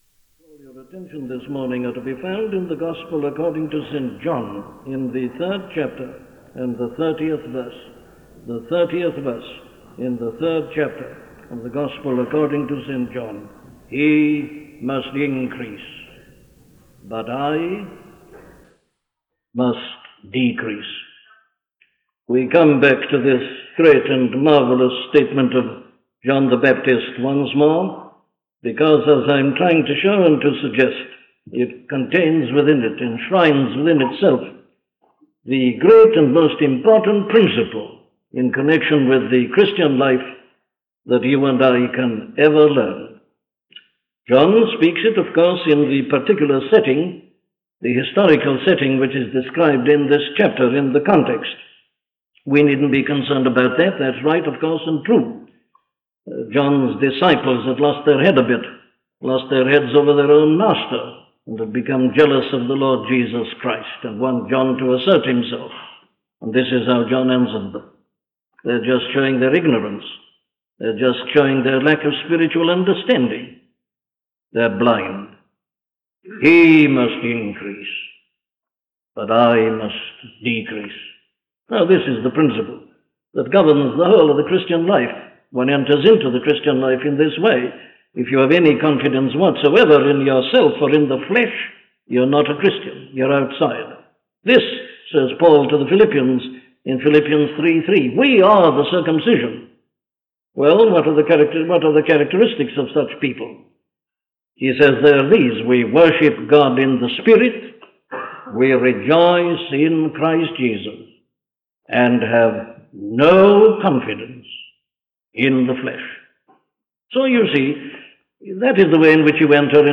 Look Unto Jesus - a sermon from Dr. Martyn Lloyd Jones
Listen to the sermon on John 3:30 'Look Unto Jesus' by Dr. Martyn Lloyd-Jones